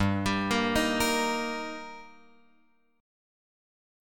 Gm#5 chord {3 x 5 3 4 6} chord